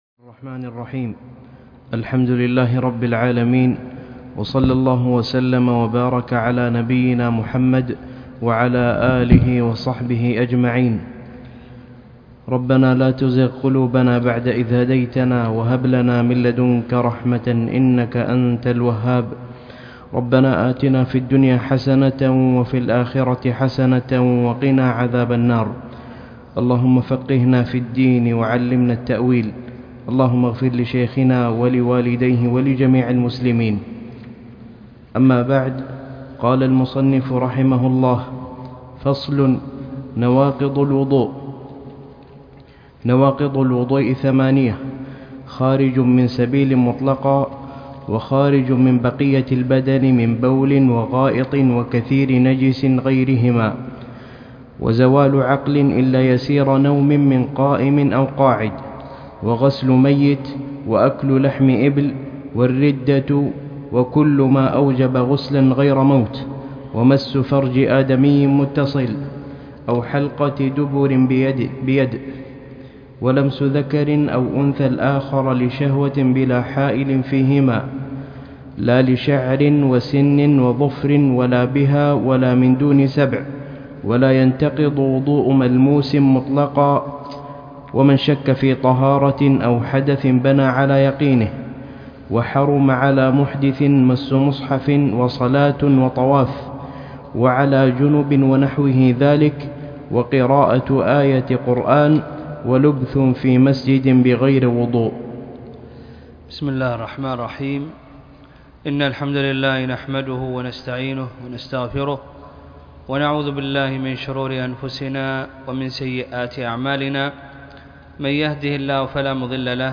الدرس ( 4) نواقض الوضوء - شرح أخصر المختصرات